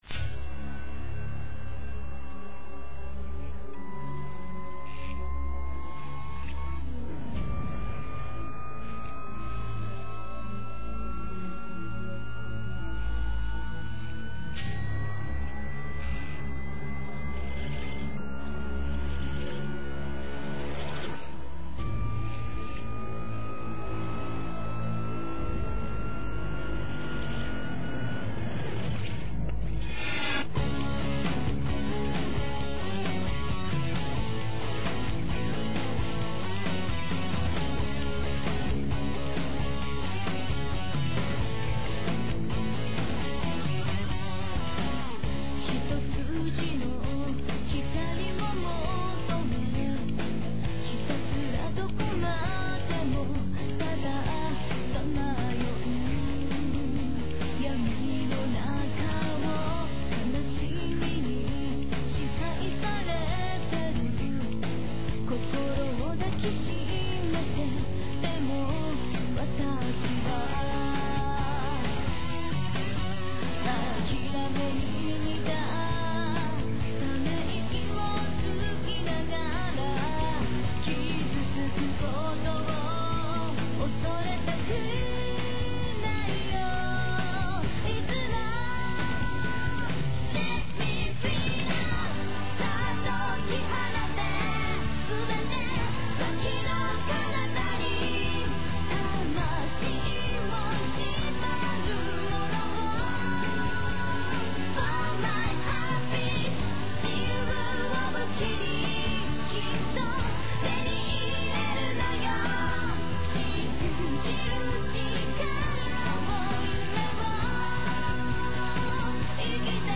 opening song